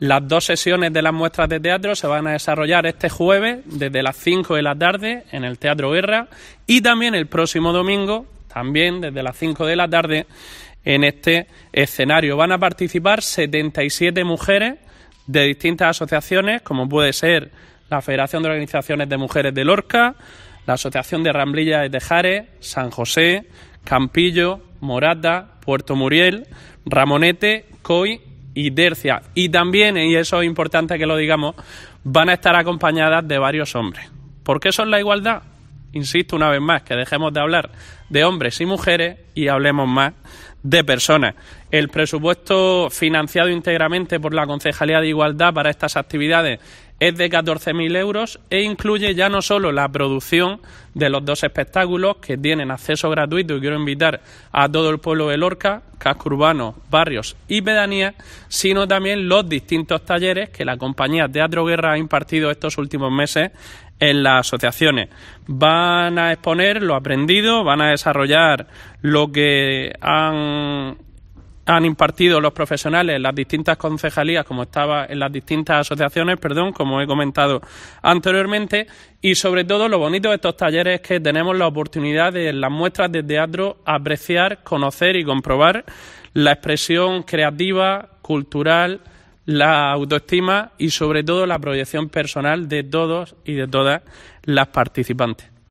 Juan Francisco Martínez, edil de Igualdad del Ayuntamiento de Lorca